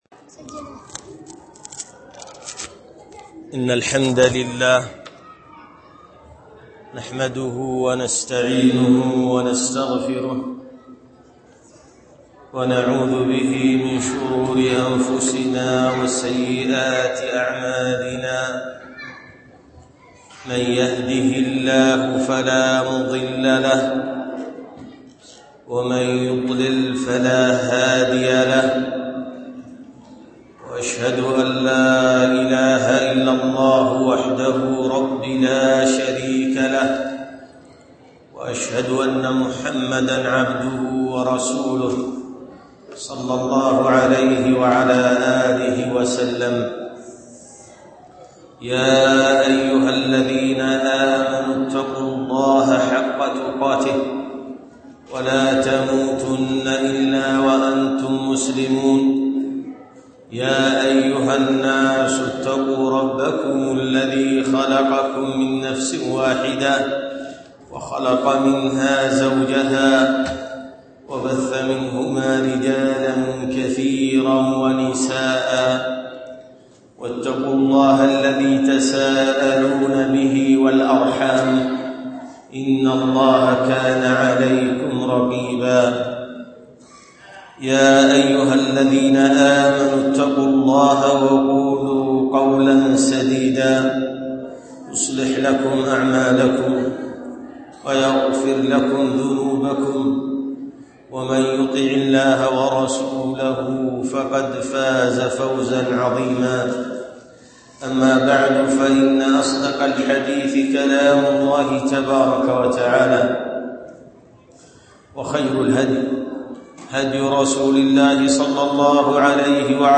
محاضرة للنساء